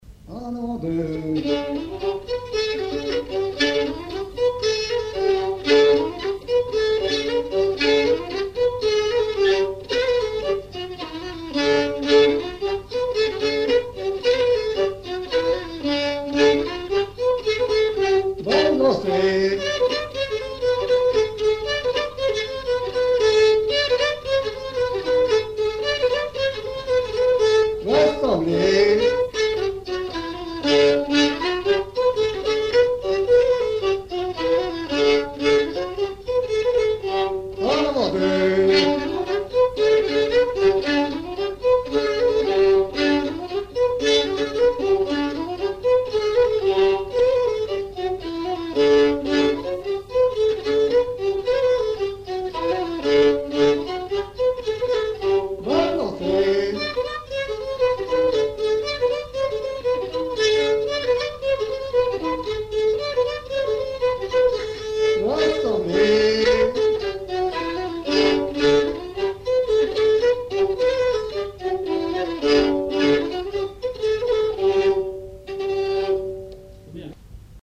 Mémoires et Patrimoines vivants - RaddO est une base de données d'archives iconographiques et sonores.
danse : branle : avant-deux
enregistrements du Répertoire du violoneux
Pièce musicale inédite